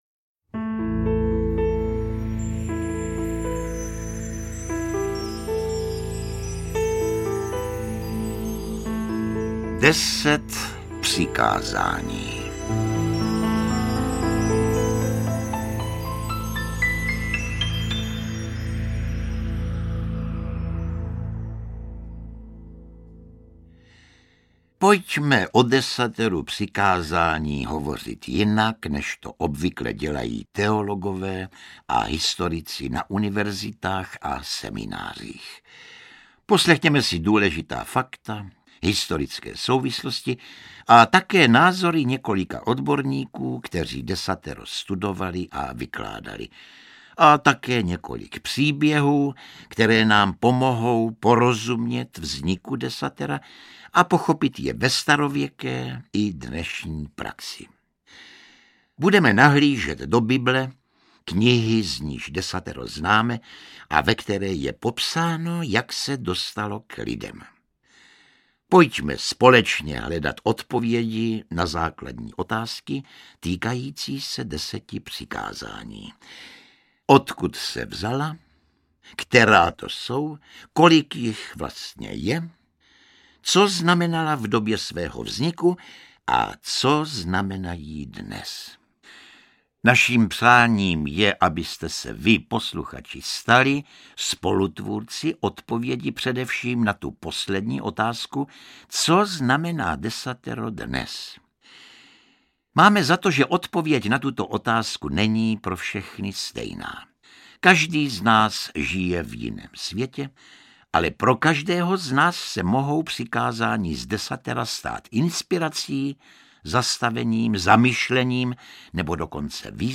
Interpret:  Josef Somr
Deset přikázání v poutavém vyprávění Josefa Somra pro chvíle rozjímání, poučení a zamyšlení!
Přesvědčivé podání Josefa Sommra.
AudioKniha ke stažení, 14 x mp3, délka 1 hod. 16 min., velikost 68,8 MB, česky